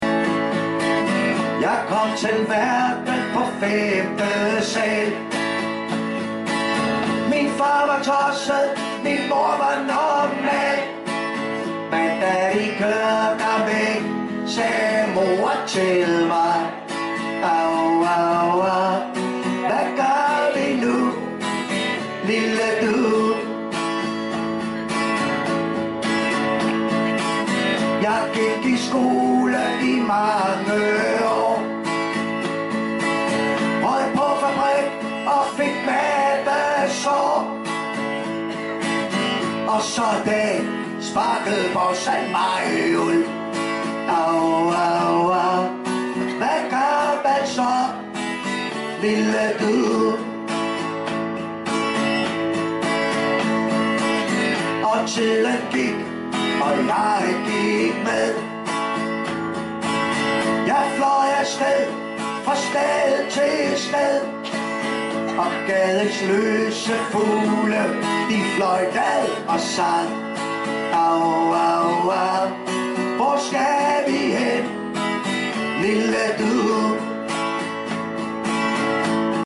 • Pop
• Singer/songwriter